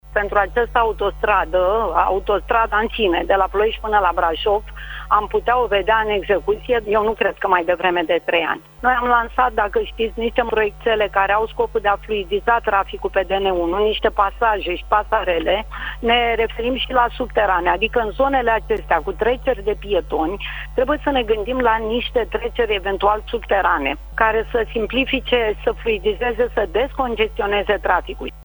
Ioniță spune că s-a pierdut până acum timp prețios, dar promite o fluidizare a traficului în zonă prin finalizarea unei serii de proiecte pe DN1, a precizat pentru Digi24 oficialul companiei de autostrăzi, Mariana Ioniță: